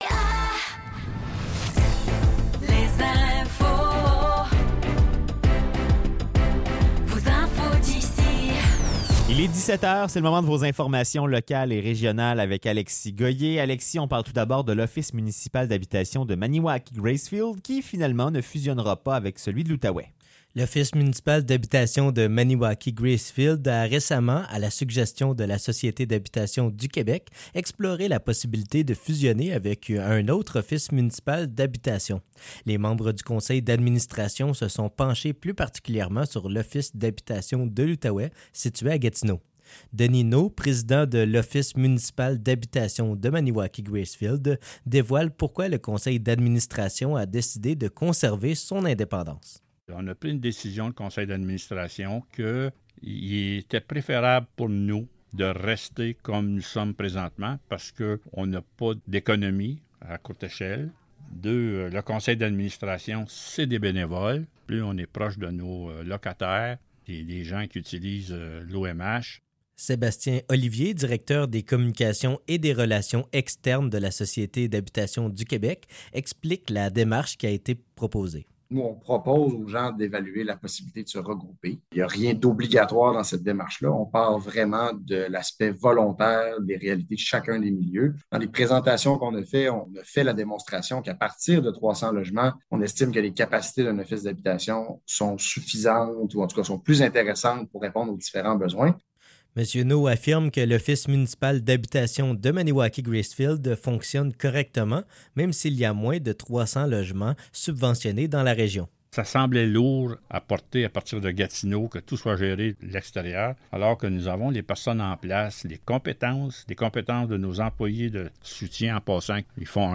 Nouvelles locales - 20 décembre 2023 - 17 h